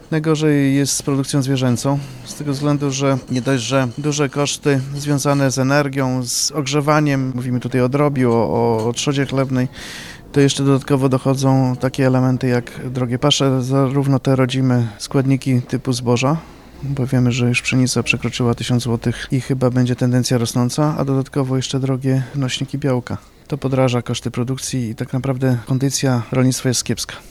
Debata rolna w Nowej Soli